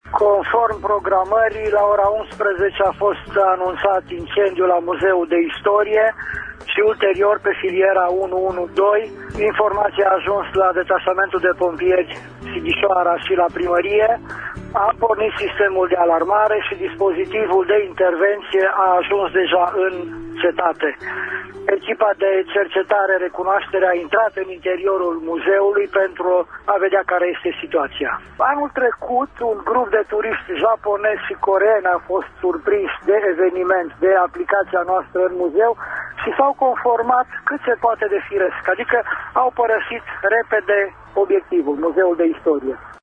în direct, azi la emisiunea Pulsul Zilei: